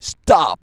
STOP.wav